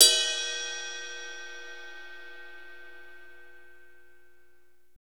Index of /90_sSampleCDs/Northstar - Drumscapes Roland/CYM_Cymbals 2/CYM_F_T Cyms x